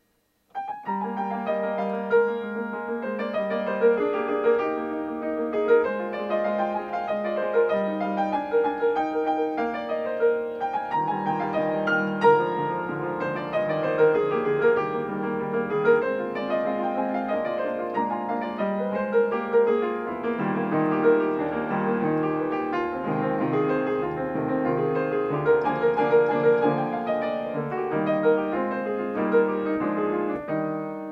冒頭に登場する動機が楽曲中絶え間なく繰り返される固着動機の無休動的面白さを探求したフィナーレ。
＜＜＜確認のためだけの下手なmp3＞＞＞